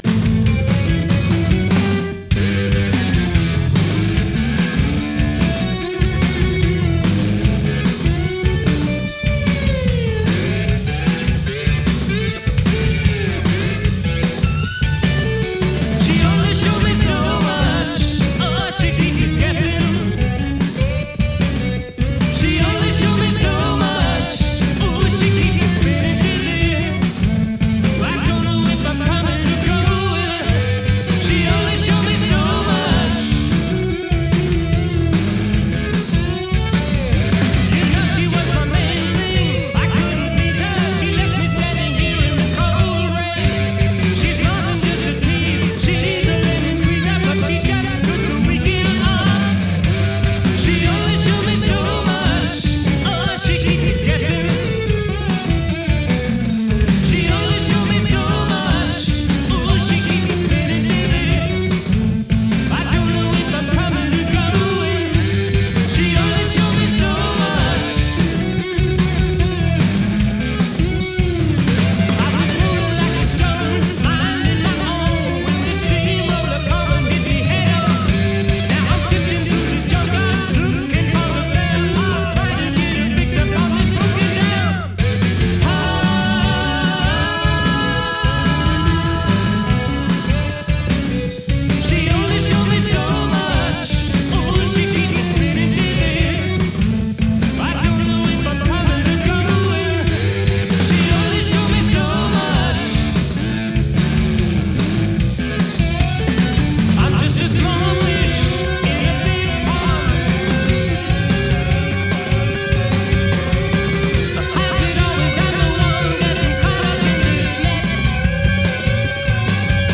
They were a small band with a big sound (round and loud)
on keyboards and vocals
bass guitar and vocals
drums and vocals